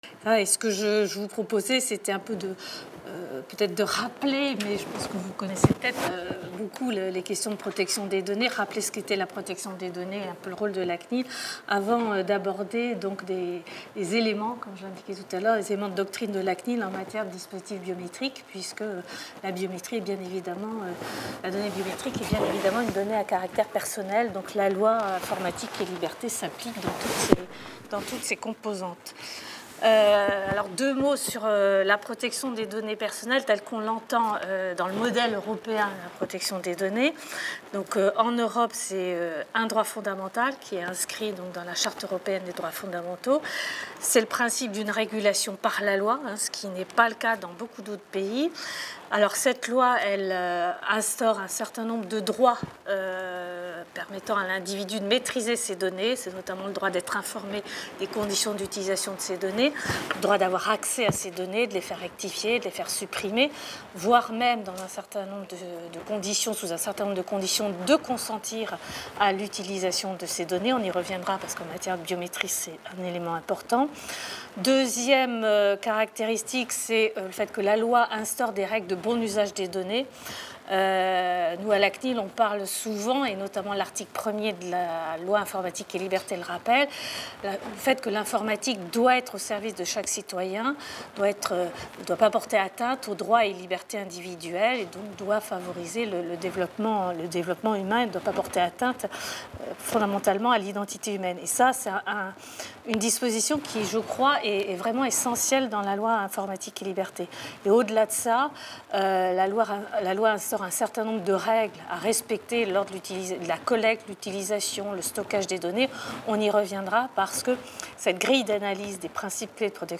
Biométrie et protection des données personnelles Séminaire interdisciplinaire organisé à l’Institut Français de l’Éducation de l’École Normale Supérieure de Lyon.